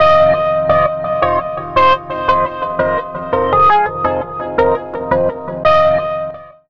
04 Boincing 170 Ab.wav